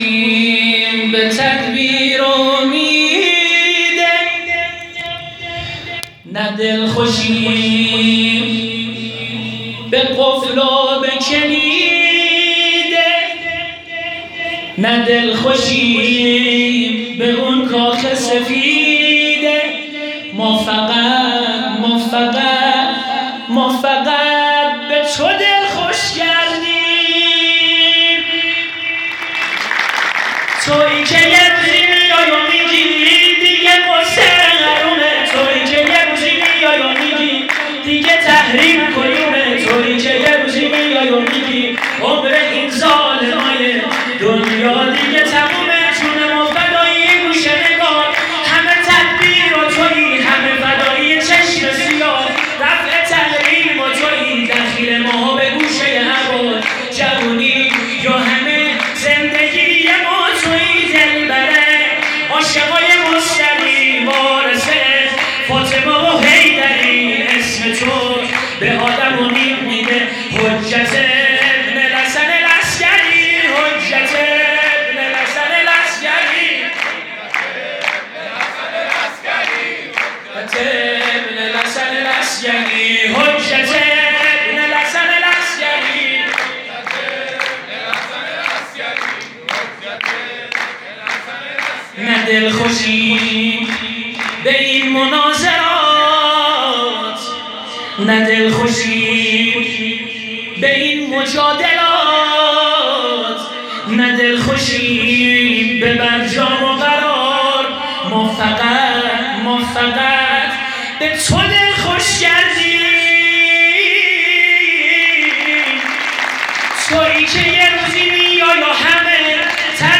مولودی خوانی های نیمه شعبان 1395
مولودی خوانی های مداحان حیدروا به مناسبت نیمه شعبان